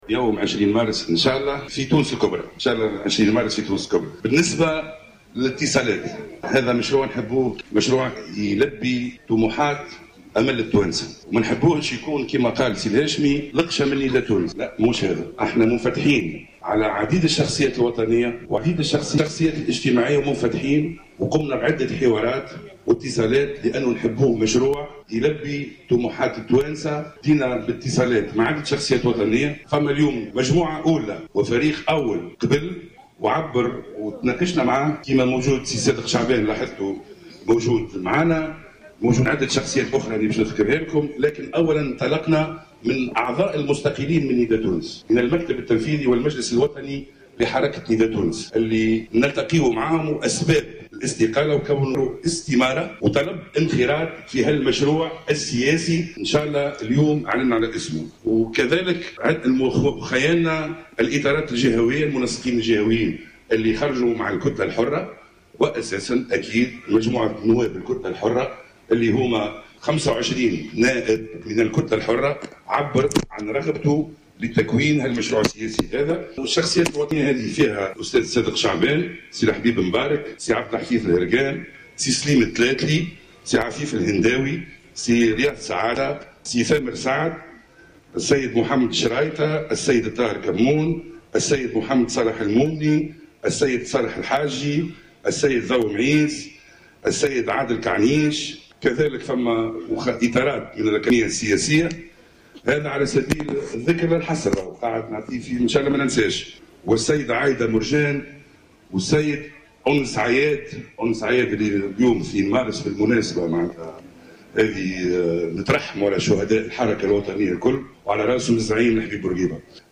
في تصريح